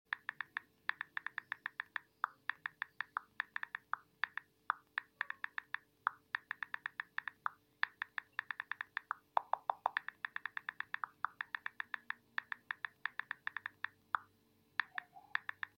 Fast iphone typing Sound sound effects free download
Fast iphone typing - Sound Effect